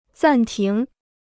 暂停 (zàn tíng) Free Chinese Dictionary